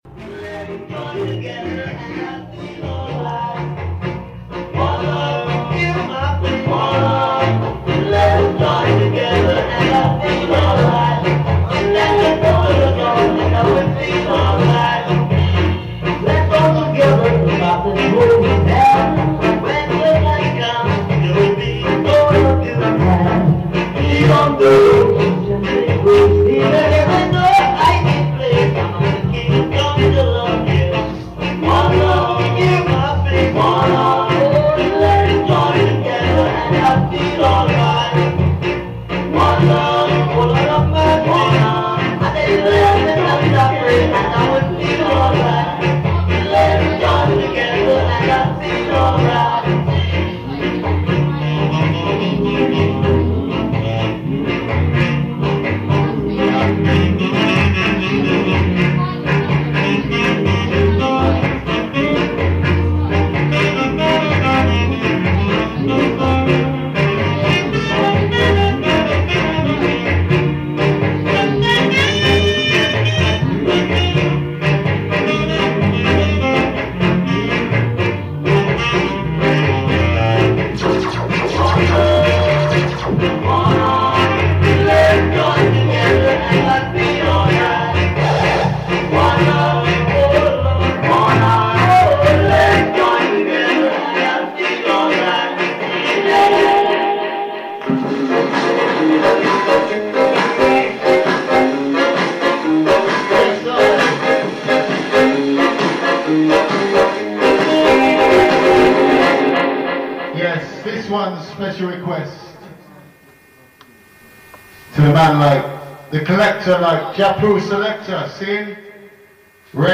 Friday 14th Jan 2011 in session on Lana Soundsystem inside Cafe B, 566 Cable St, London E1. Rootikal vibes and positive vibrations
on mike duty
Big up Talawa crew, enjoy the vibes from ska to steppa.